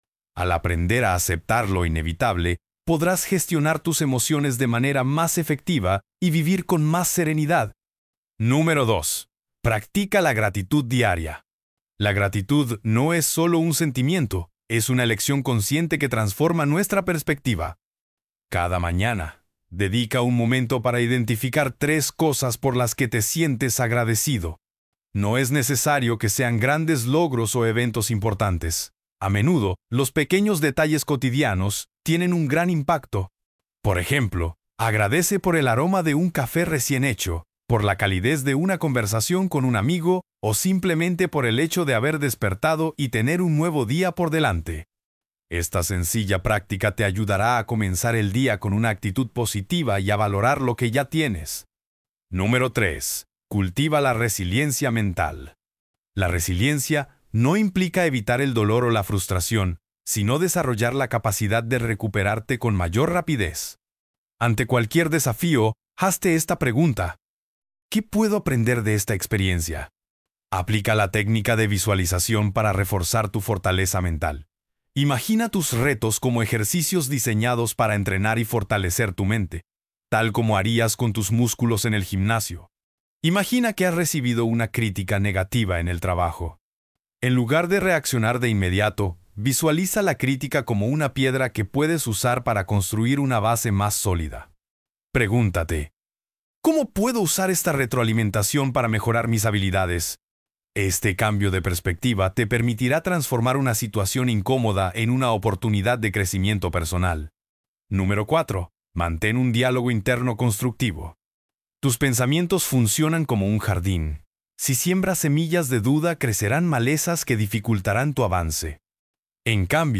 Clonada normal 2.mp3